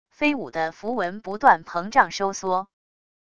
飞舞的符文不断膨胀收缩wav音频